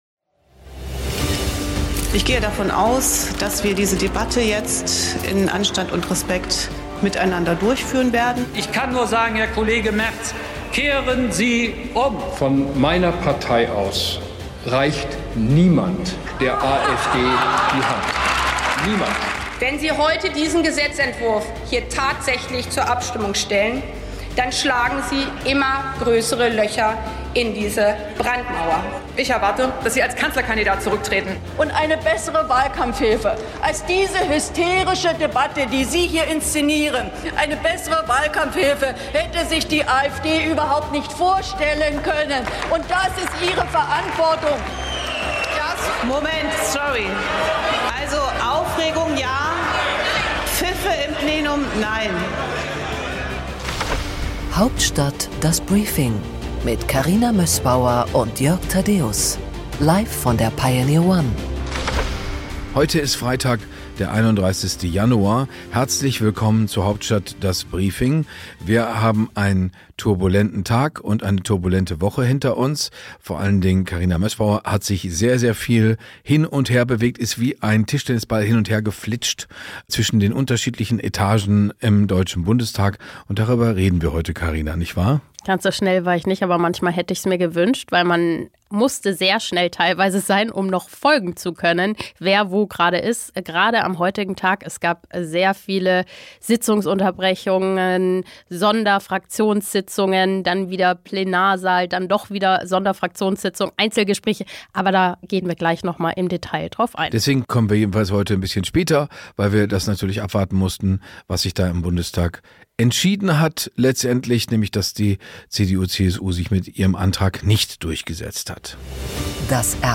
Im Interview der Woche